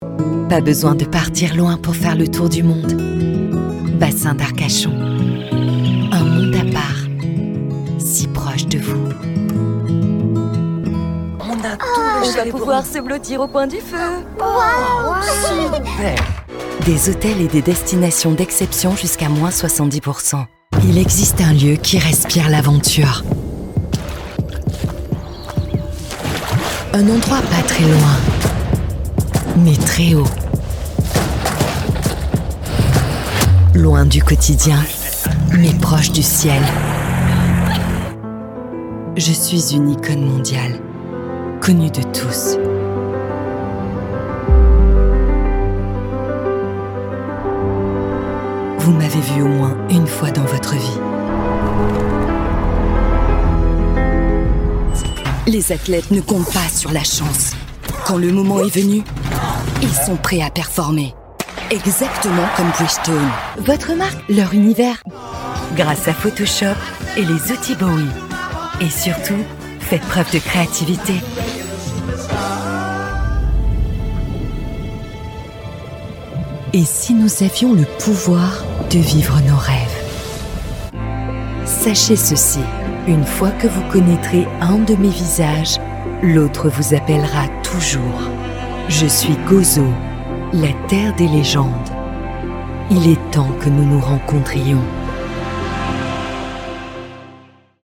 Profonde, Naturelle, Distinctive, Accessible, Polyvalente
E-learning